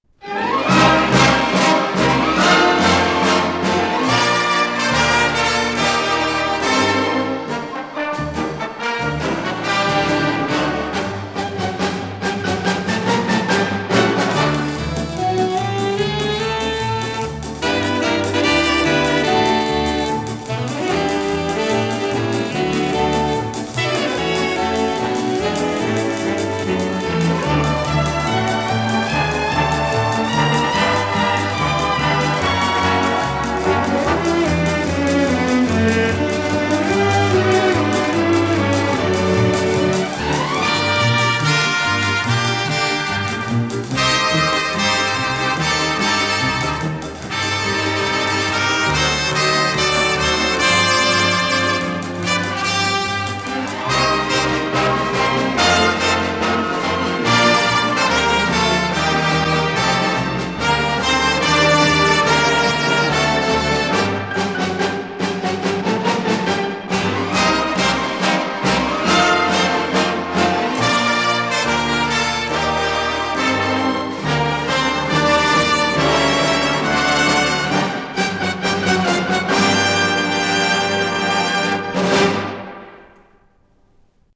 с профессиональной магнитной ленты
ПодзаголовокЗаставка, соль минор
Скорость ленты38 см/с